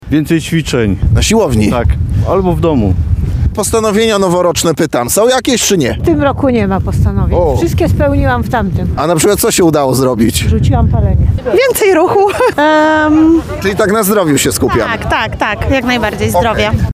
Zapytaliśmy mieszkańców Lublina o ich plany i nadzieje związane z nowym rokiem. Wiele osób ma również noworoczne postanowienia.